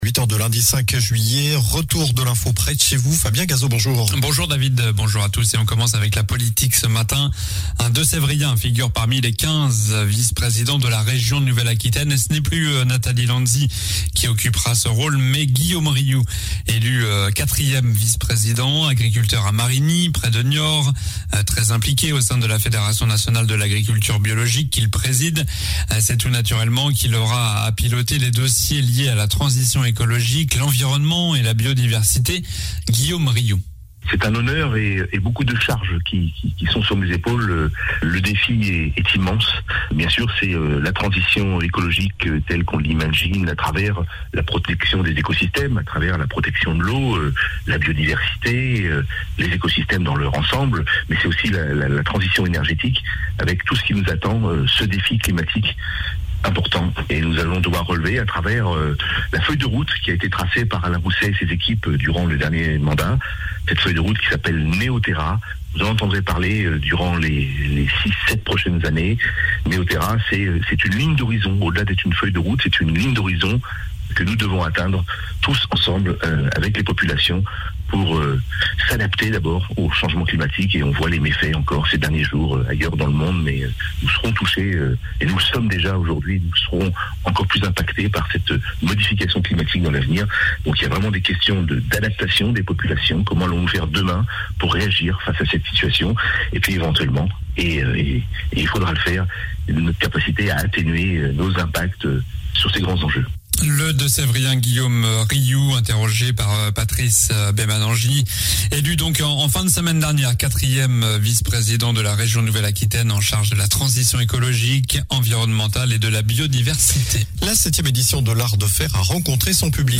Journal du lundi 05 juillet (matin)